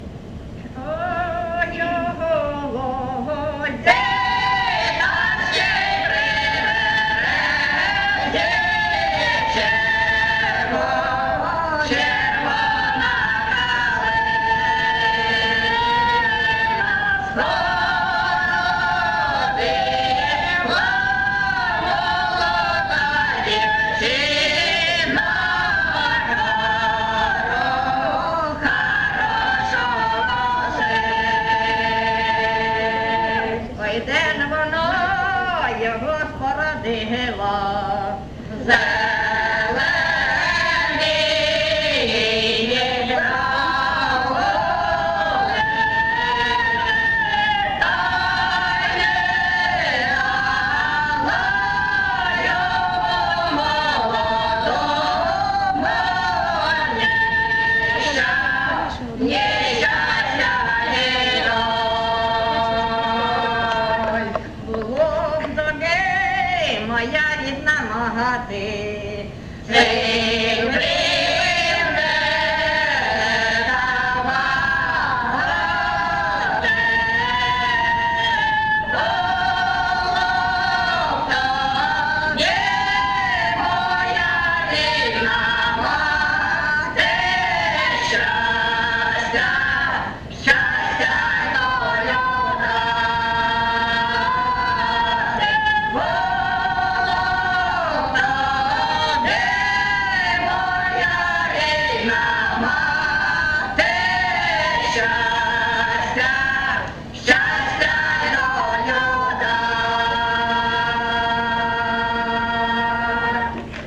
ЖанрПісні з особистого та родинного життя
Місце записус. Очеретове, Валківський район, Харківська обл., Україна, Слобожанщина